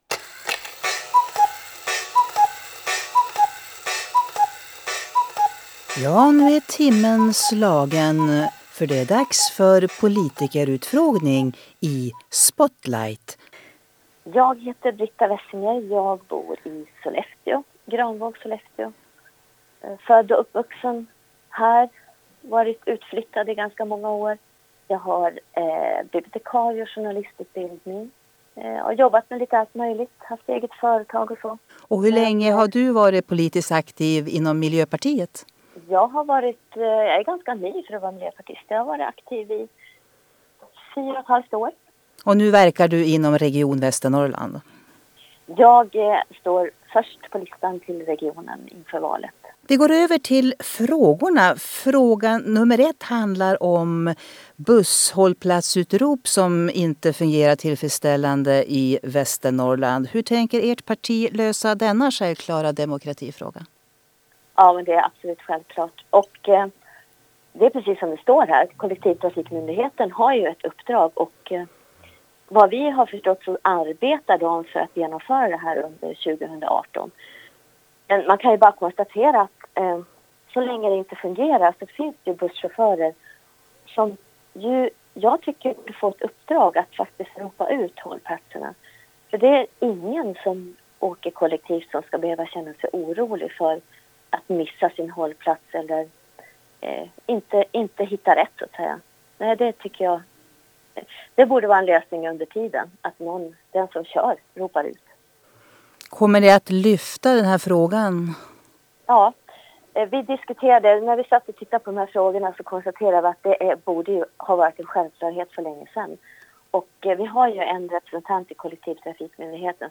05.Politikerutfragning_5_MP_Brita_Wessinger.mp3